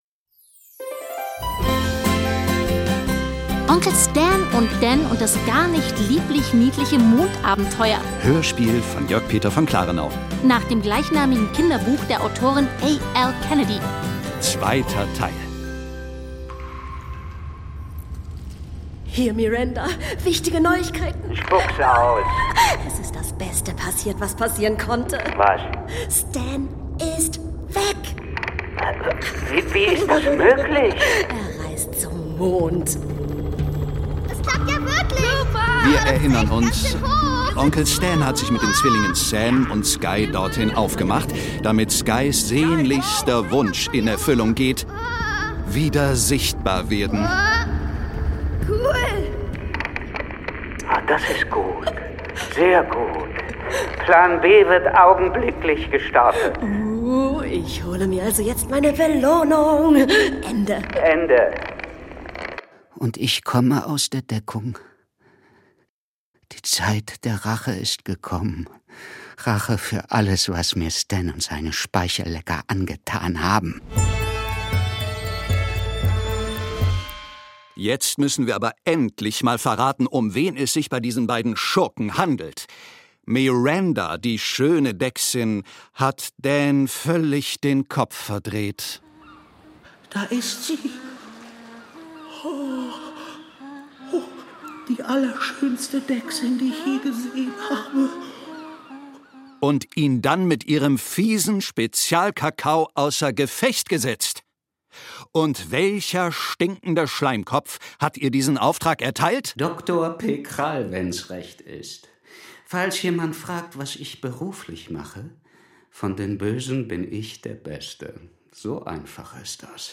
Kinderhörspiel: Onkel Stan und Dan und das gar nicht lieblich-niedliche Mondabenteuer (Teil 2) ~ Hörspiele, Geschichten und Märchen für Kinder | Mikado Podcast